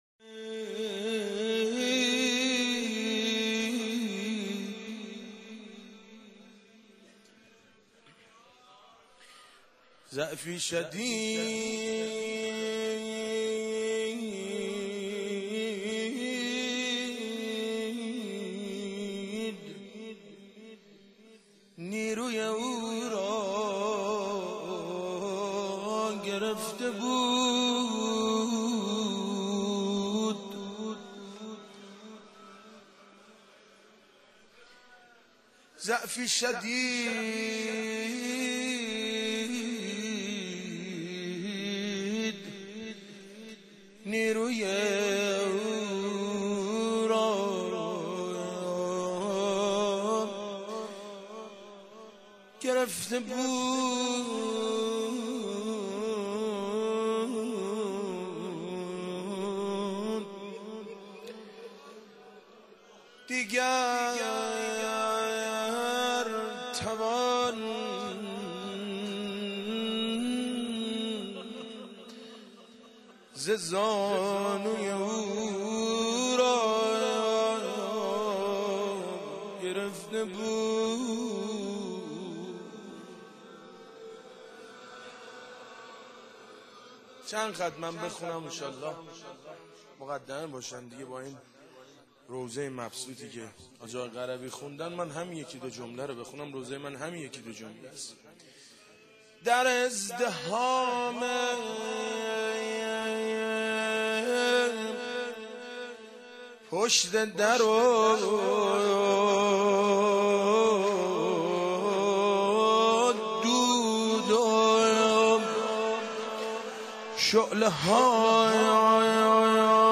مداحی و نوحه
روضه، شهادت حضرت زهرا(س)